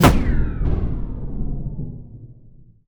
death3.wav